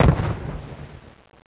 city_battle19.wav